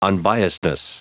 Prononciation du mot unbiasedness en anglais (fichier audio)
Prononciation du mot : unbiasedness